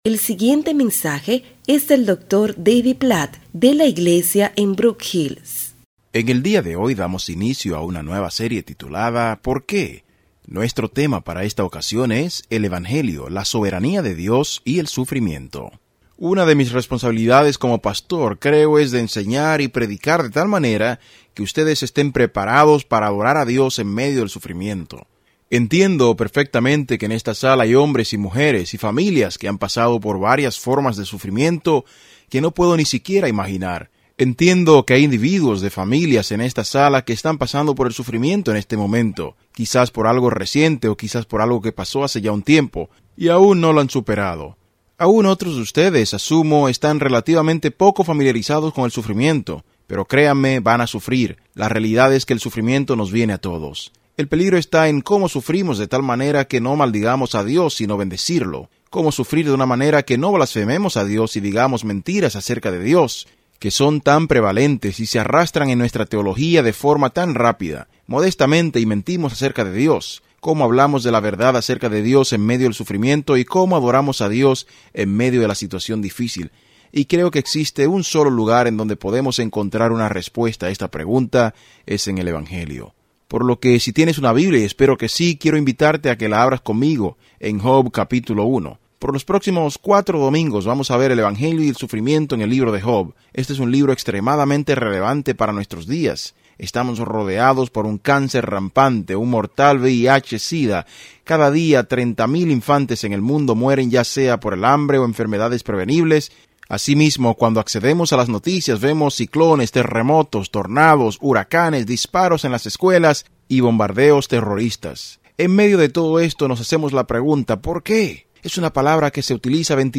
En este mensaje sobre Job 1–2, el pastor David Platt nos enseña que Dios es supremamente soberano sobre toda la creación.